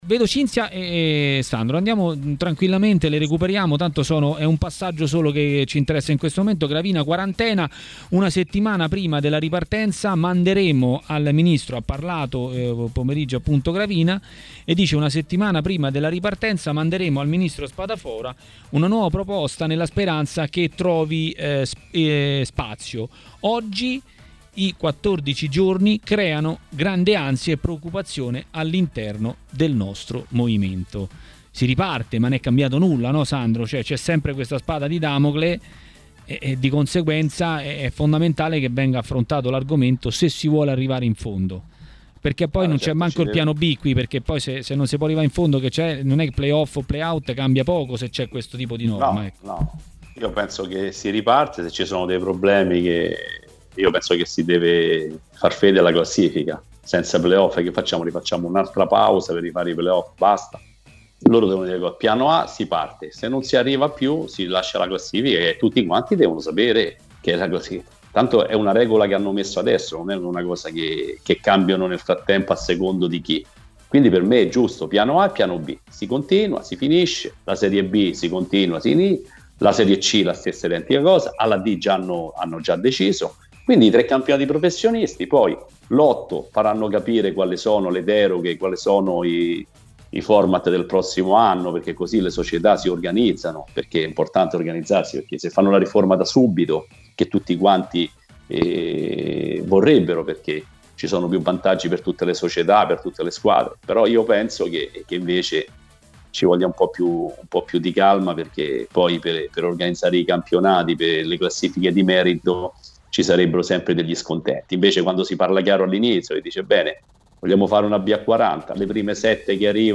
Antonio Paganin, ex calciatore, ha parlato di mercato e non solo a Maracanà, nel pomeriggio di TMW Radio.